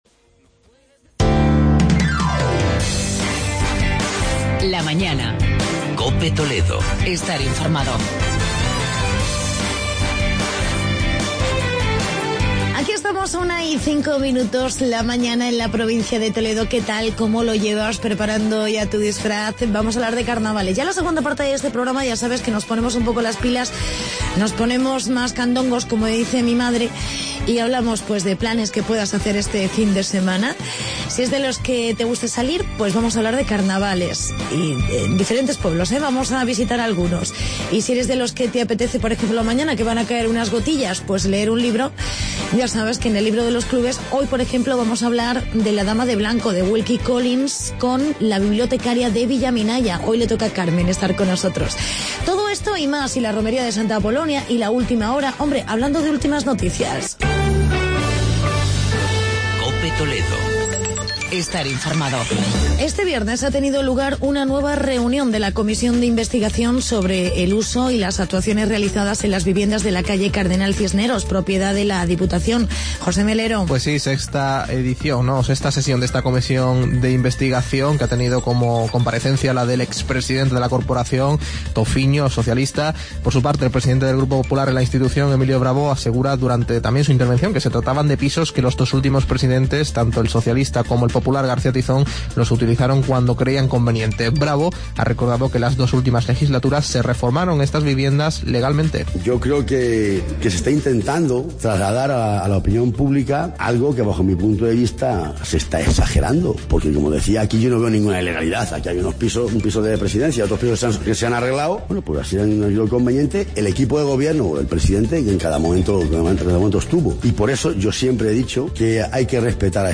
reportajes sobre Carnaval en diferentes pueblos y Romería Santa Apolonia.